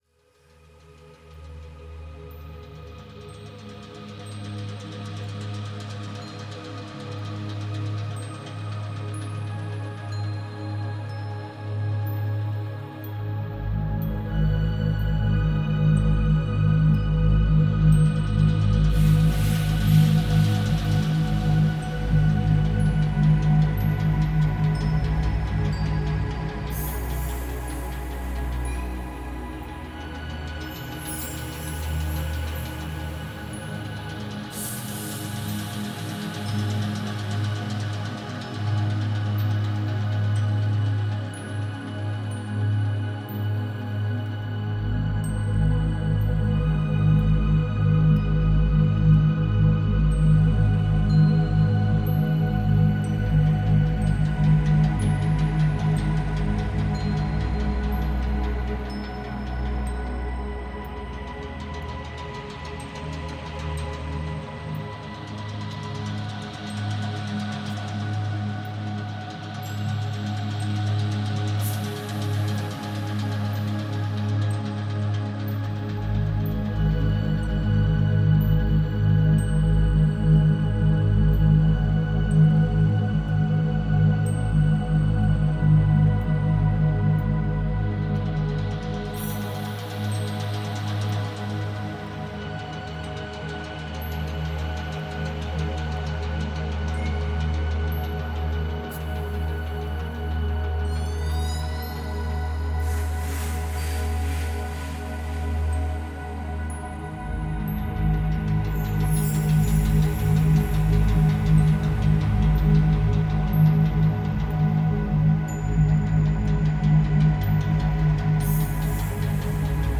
🎶 Детские песни / Музыка детям 🎵 / Музыка для новорожденных